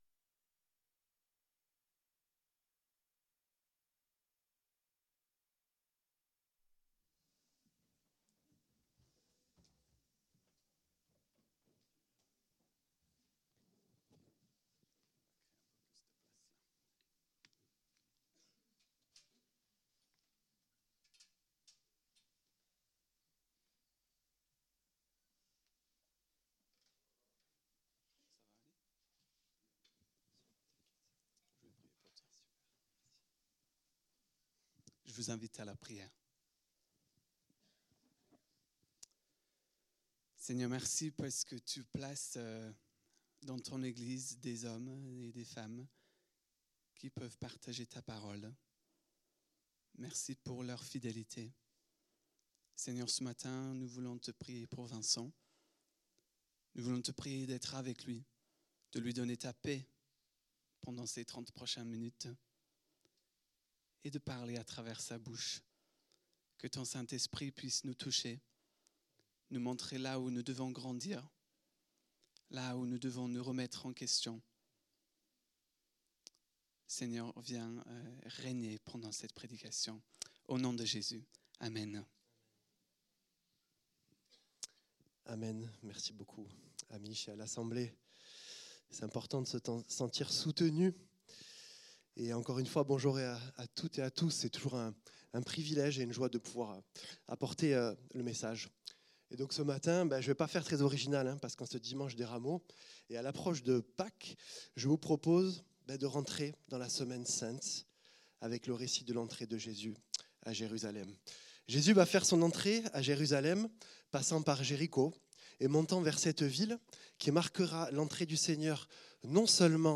Culte du dimanche 29 mars 2026, prédication